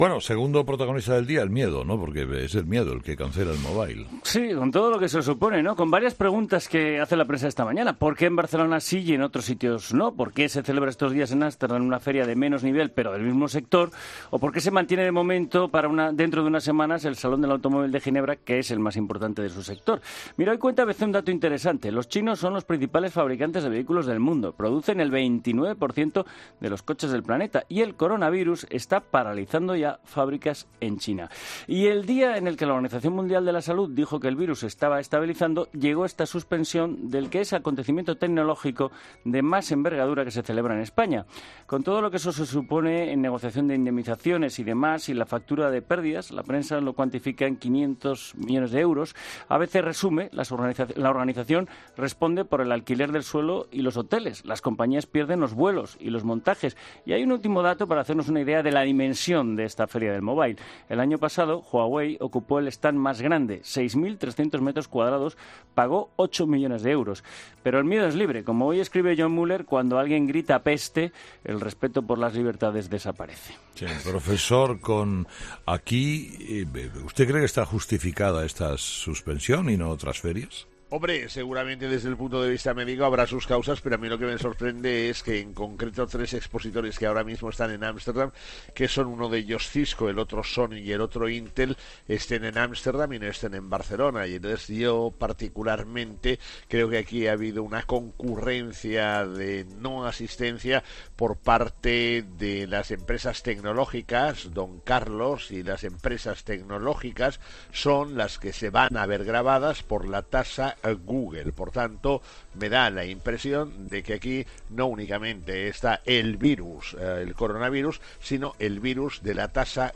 El profesor Gay de Liébana analiza la cancelación del Mobile en 'Herrera en COPE'